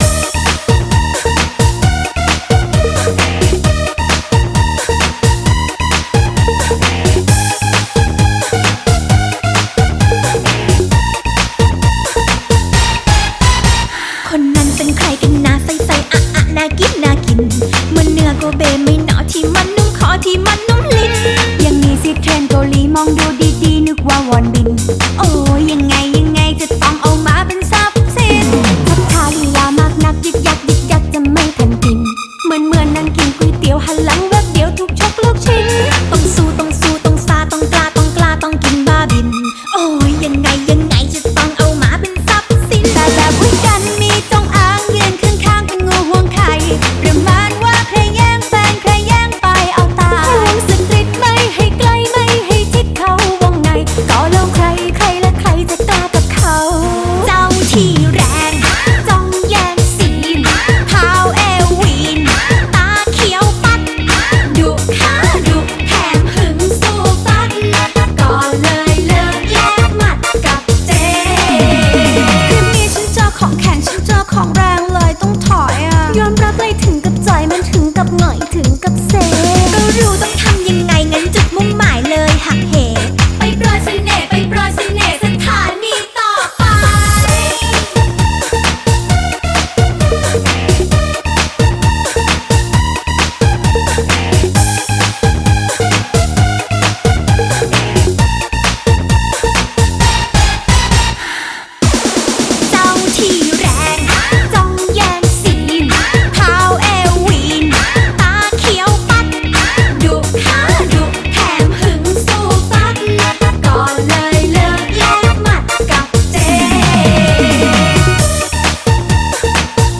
BPM132
Audio QualityMusic Cut
help. the song's too catchy.